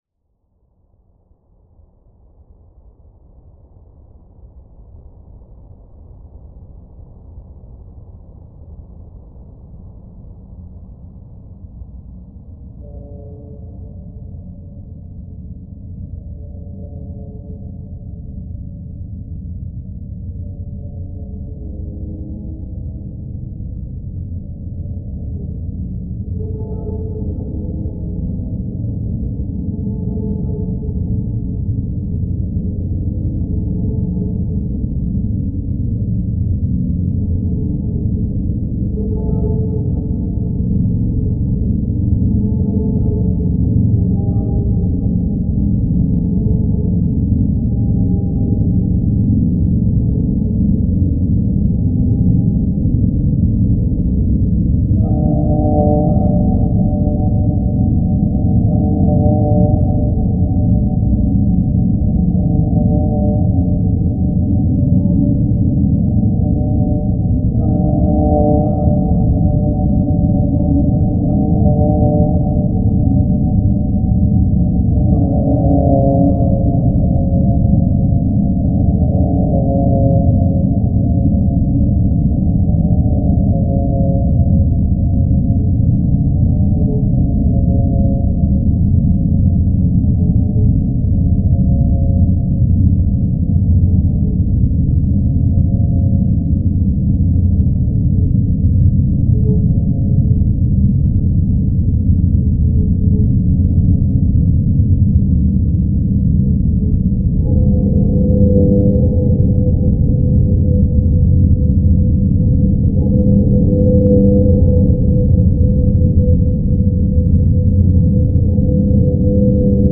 File under: Dark Ambient / Experimental